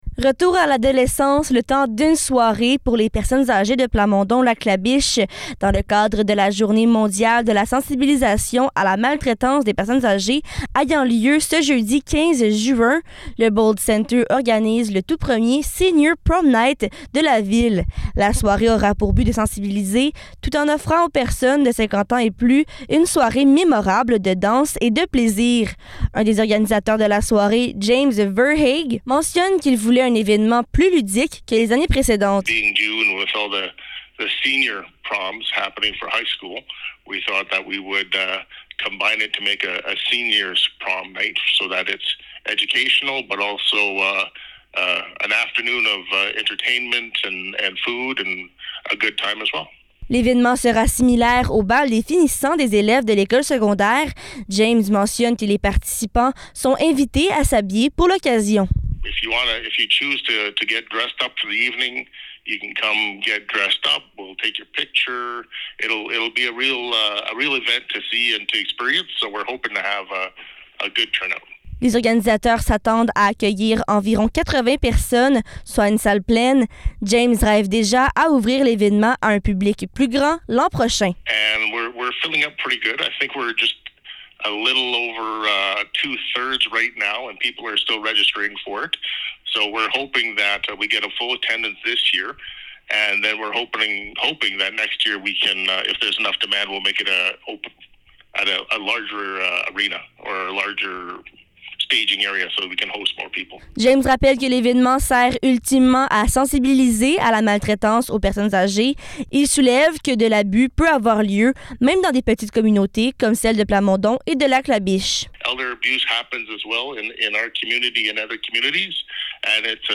Reportage-Senior-Prom-Night.mp3